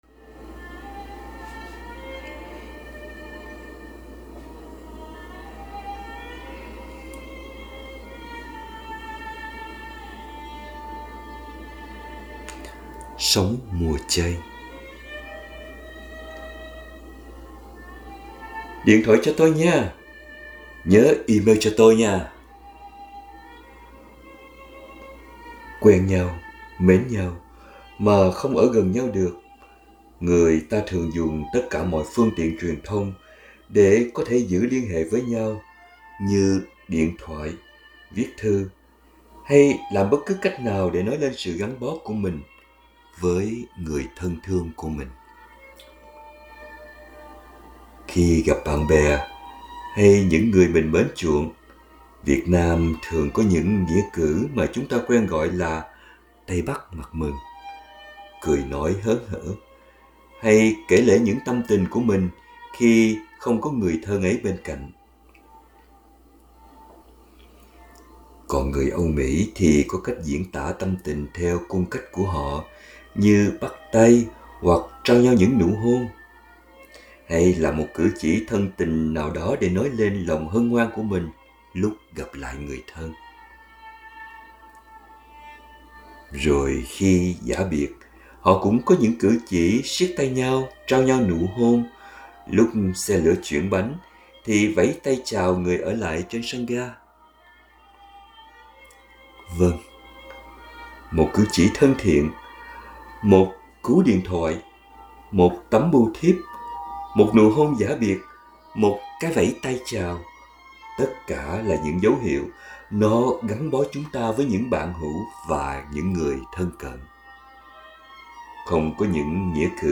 2025 Audio Suy Niệm https